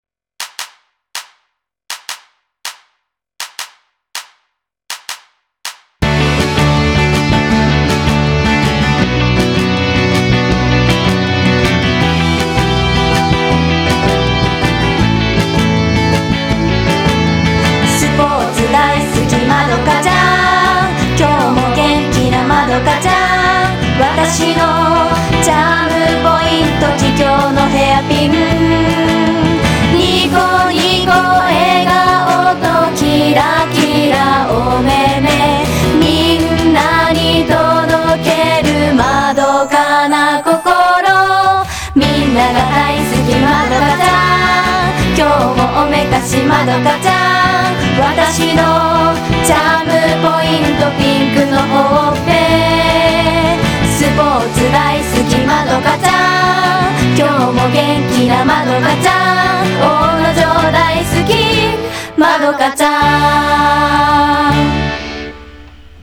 作詞・作曲は筑紫中央高校軽音楽部のみなさんで、元気一杯のまどかちゃんにぴったりな軽快な曲です。